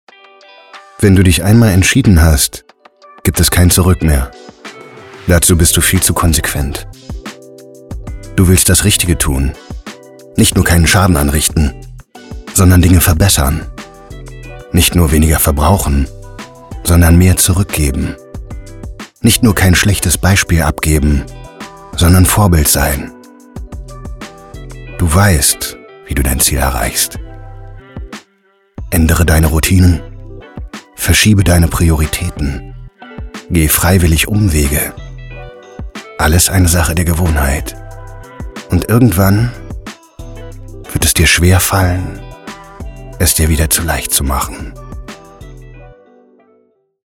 Werbung (Mood): Das Richtige 35, EPM MP3